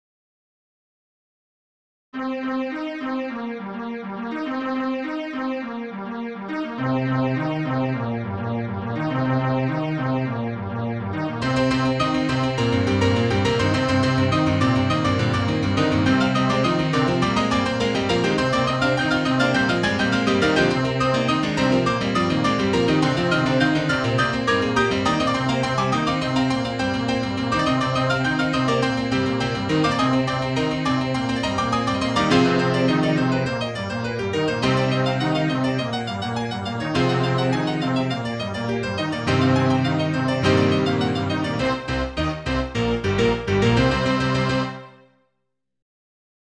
Title Tumbling Opus # 7 Year 0000 Duration 00:00:46 Self-Rating 3 Description Another one from my teen years. It’s not very good, but I wanted to make sure I had it here for archival purposes. mp3 download wav download Files: wav mp3 Tags: Piano, Digital Plays: 1830 Likes: 0